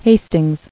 [Has·tings]